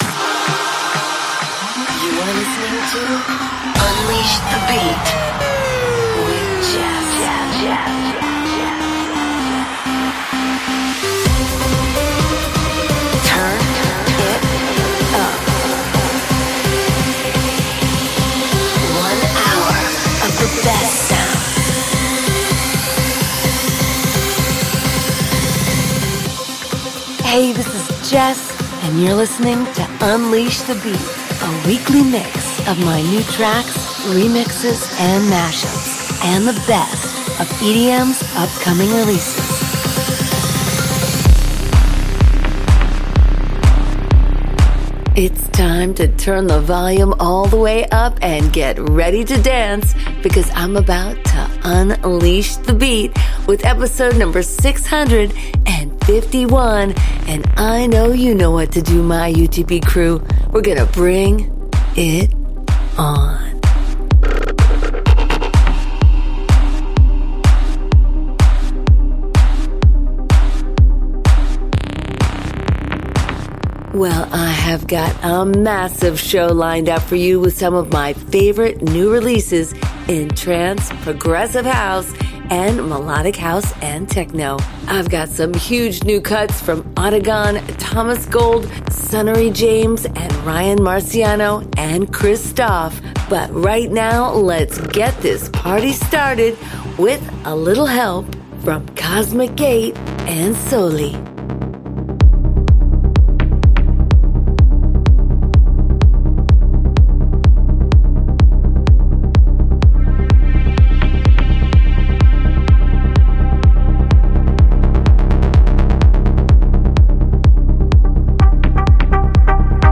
Trance-Melodic House-Techno